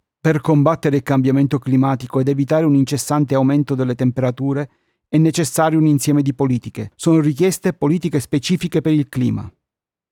Italian speaker and voice artist, warm voice, young, middle, old, character voices, medical narration, e-learning, ads, commercial, audiobooks, IVR and phone system
Sprechprobe: Industrie (Muttersprache):
I have a warm voice and I can do a variety of voice delivery.
Usually I work with a CAD e100s condenser microphone, a Solid State Logic 2 audio interface but I have a tube amplifier if that kind of sound is needed.